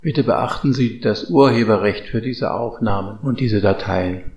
Ansage-Urheberrecht-Echo-kurz.mp3